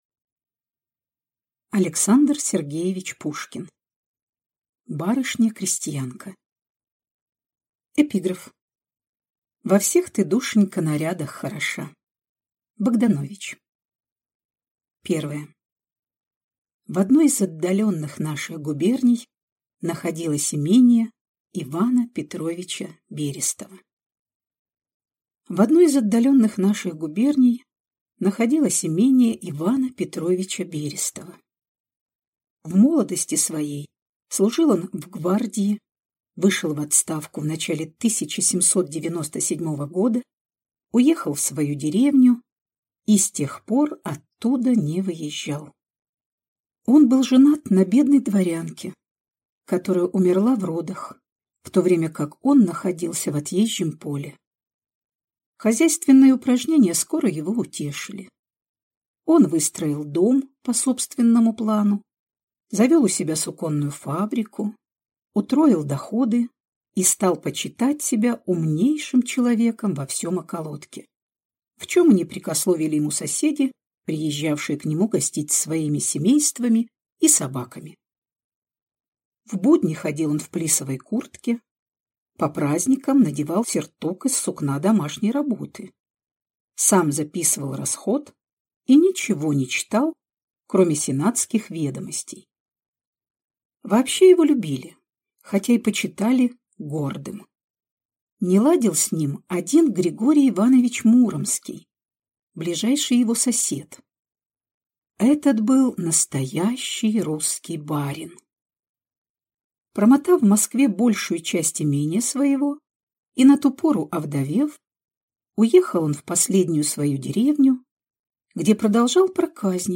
Аудиокнига Барышня-крестьянка | Библиотека аудиокниг